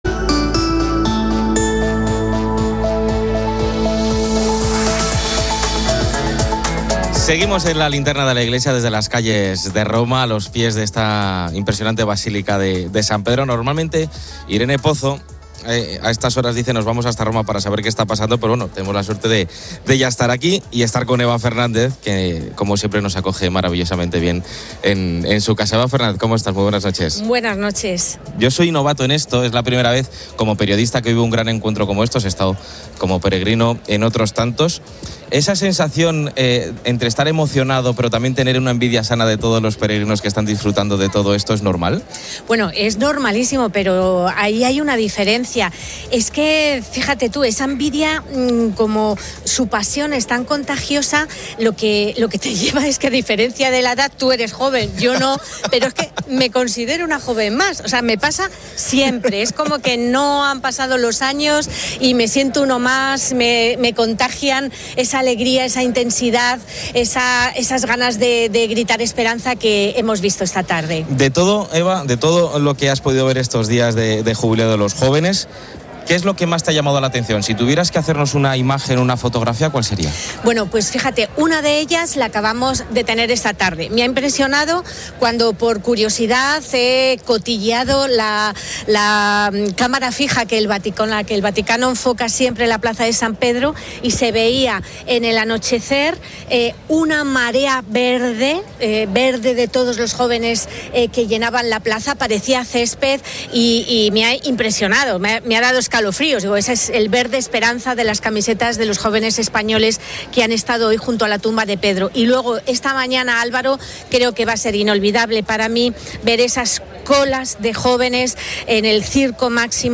Seguimos en la linterna de la iglesia desde las calles de Roma a los pies de esta impresionante basílica de San Pedro.